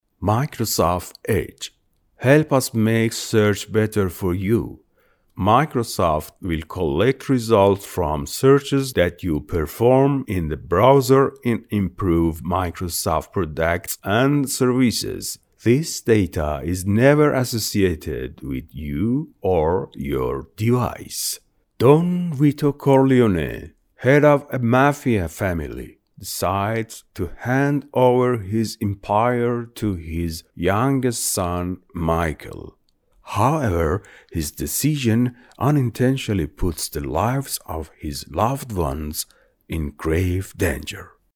Male
Adult
English Persian Accent